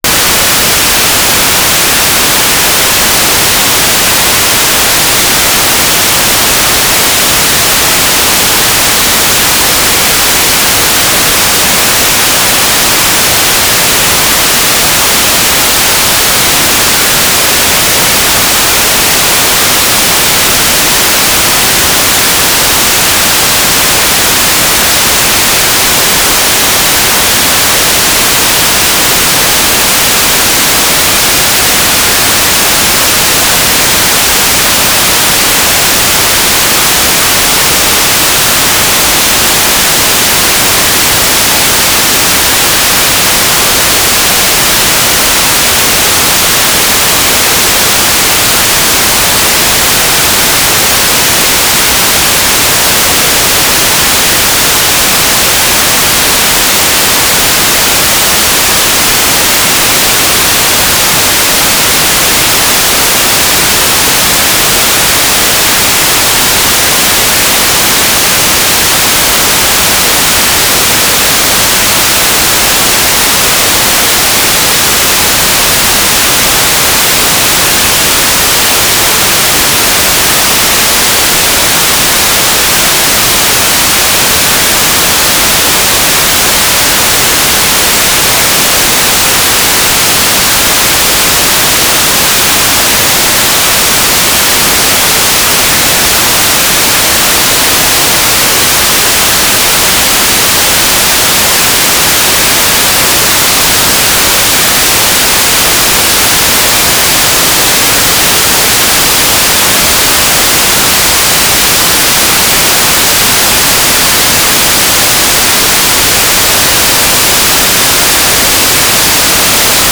"transmitter_mode": "GMSK USP",